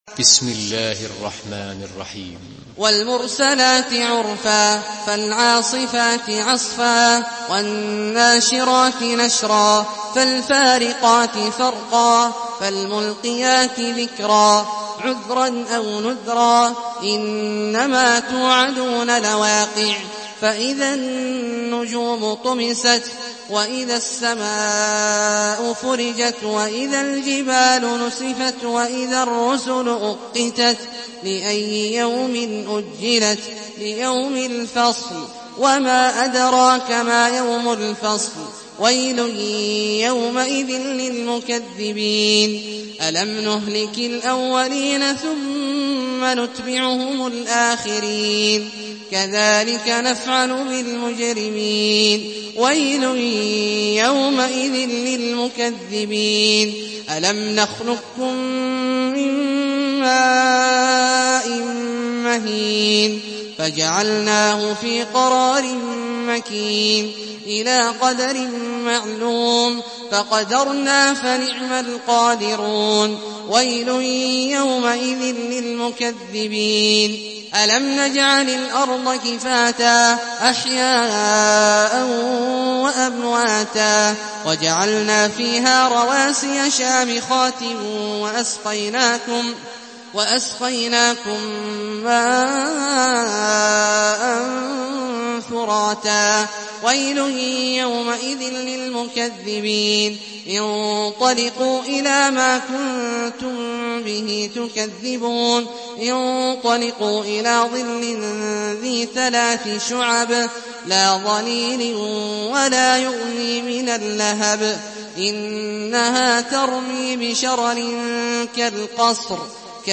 Surah المرسلات MP3 by عبد الله الجهني in حفص عن عاصم narration.
مرتل